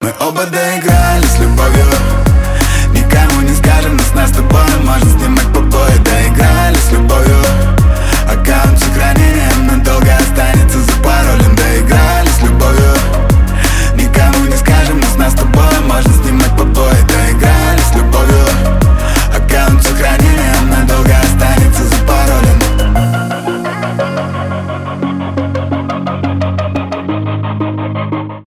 танцевальные
электронные , хип-хоп